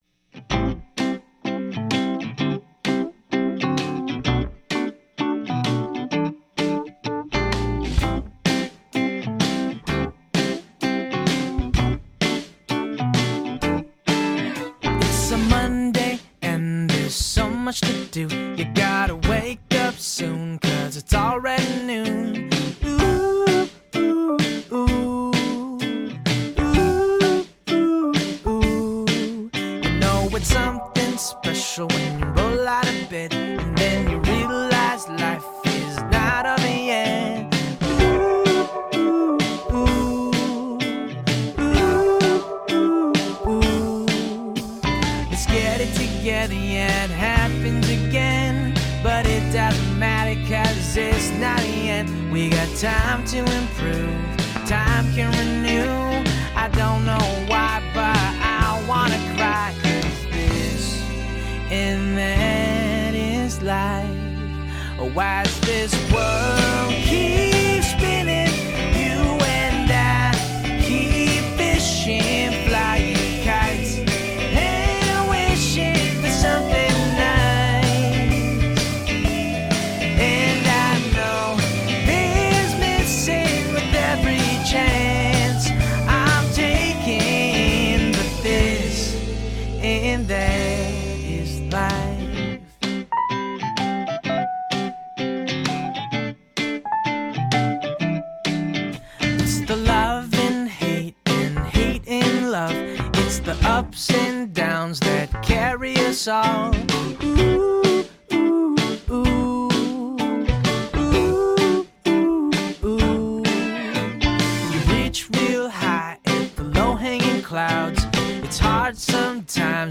amazon-employee-hold-music.mp3